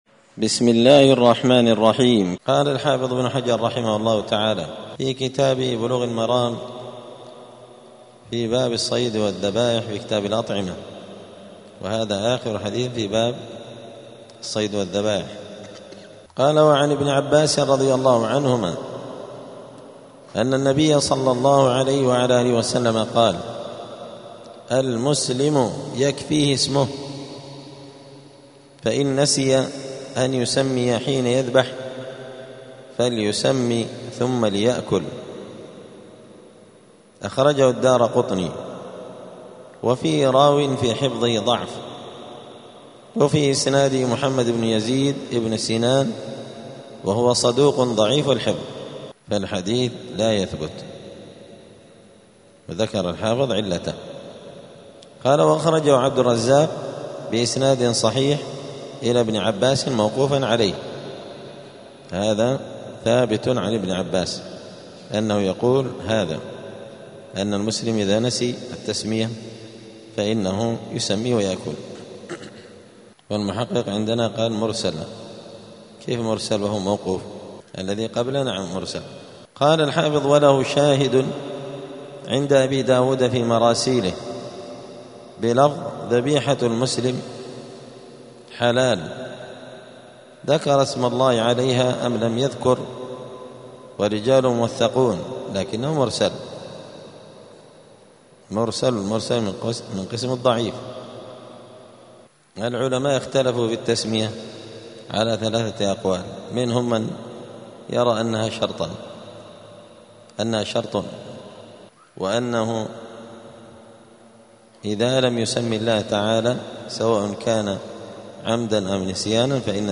*الدرس التاسع عشر (19) {باب الصيد والذبائح ترك التسمية على الذبح}*
دار الحديث السلفية بمسجد الفرقان قشن المهرة اليمن